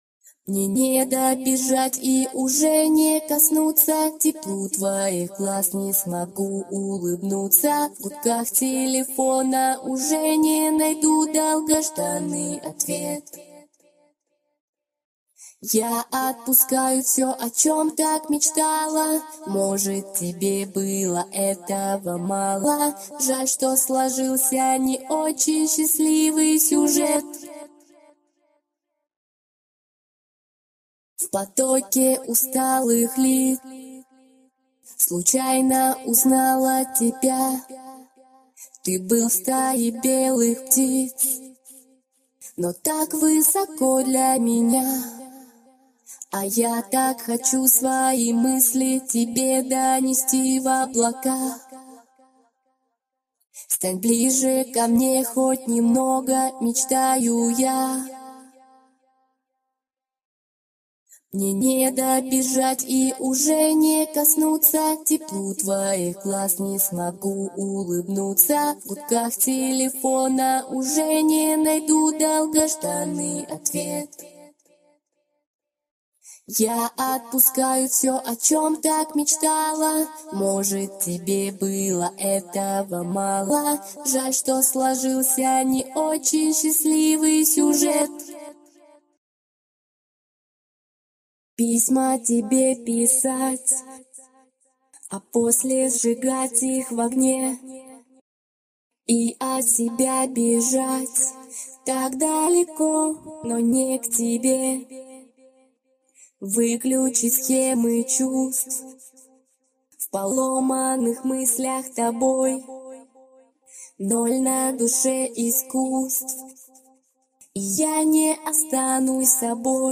Категория: Скачать Русские акапеллы